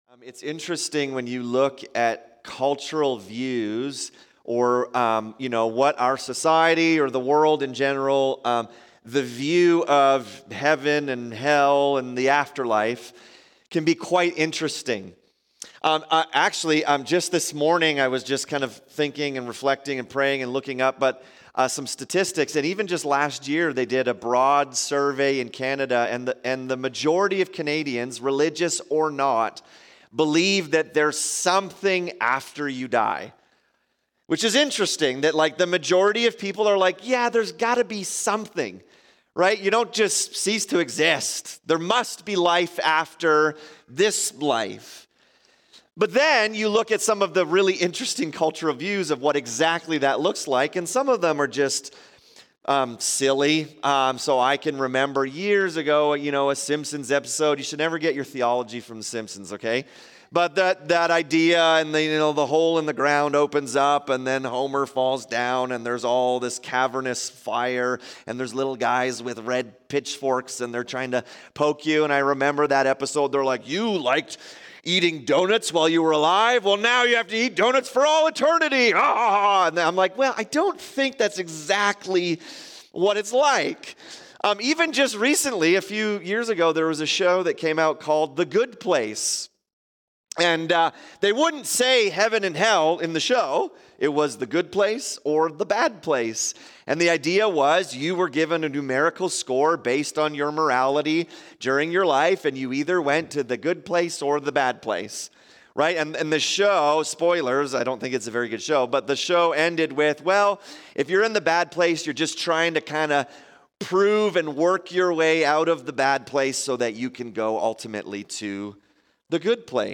In this sermon we examine the parable of the weeds. In it, Jesus reveals truths about God's justice, his patience and the final judgment.